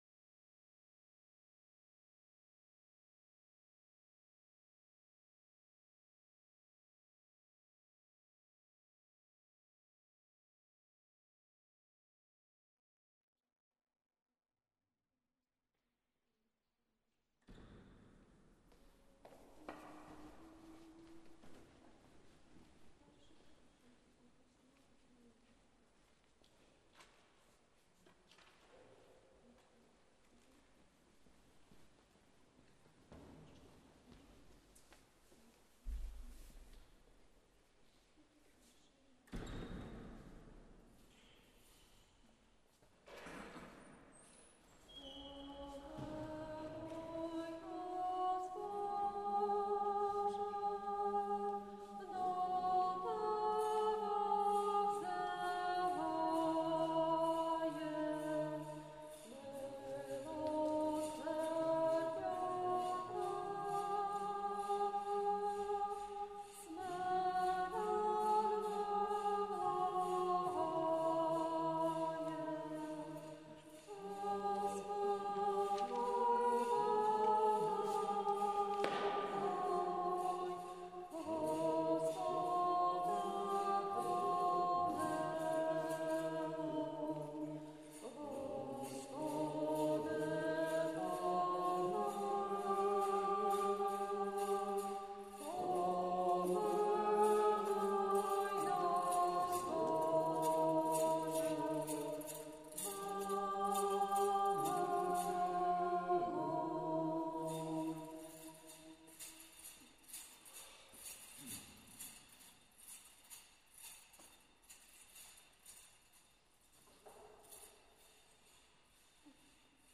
25 Березня 2020 Божественна Літургія Передосвячених Дарів